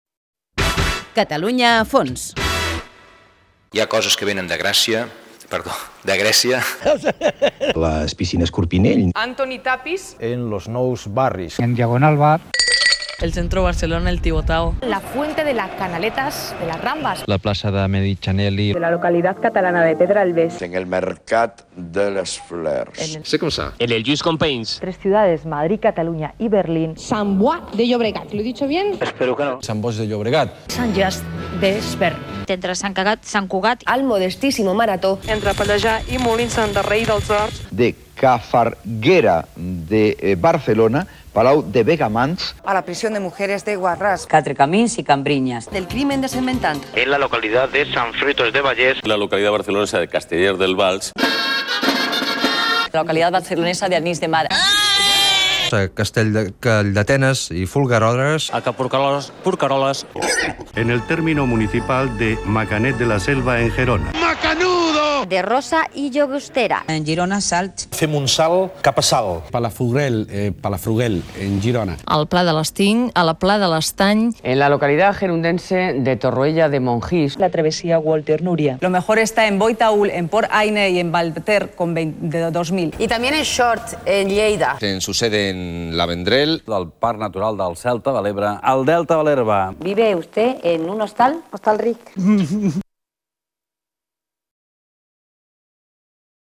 Espai "Alguna pregunta més?" (APM). Resum de talls de veu amb localitats catalanes mal pronunciades de l'any 2005
Info-entreteniment